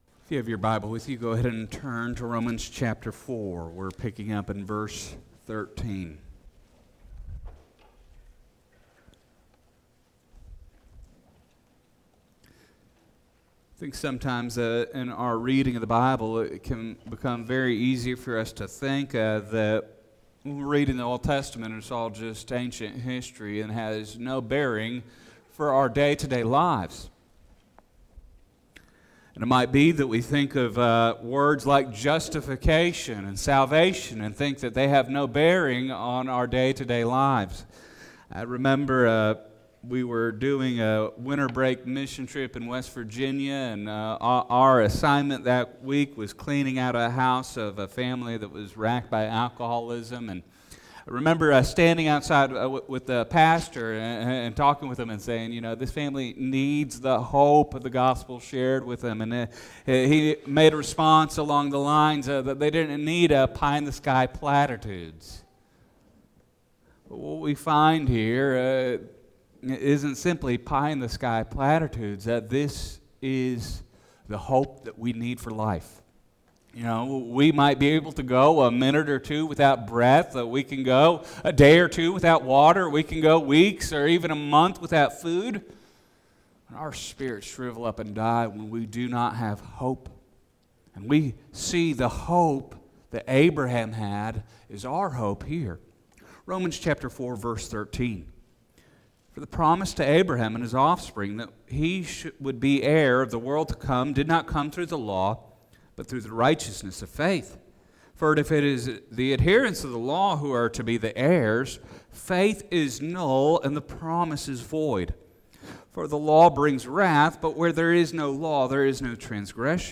Service Type: Sunday Morning Topics: death , faith , hope , resurrection